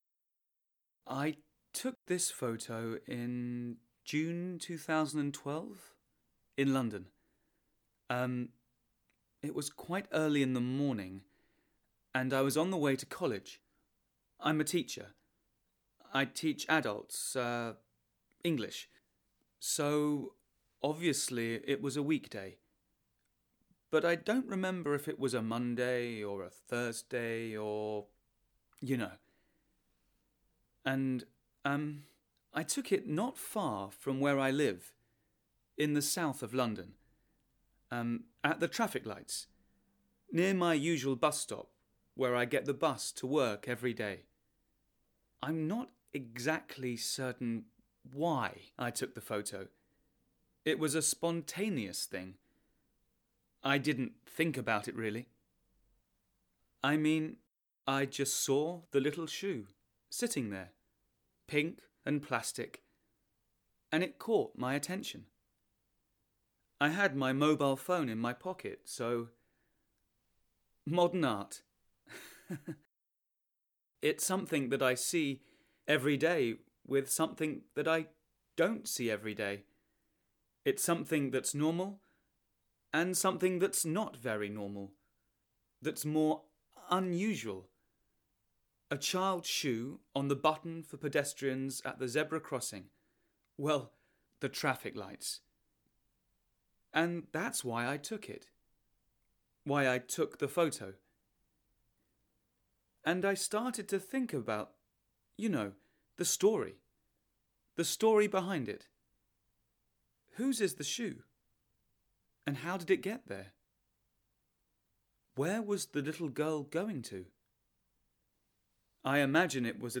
A number of activities support students in understanding a monologue in which a photographer talks about a photo he has taken.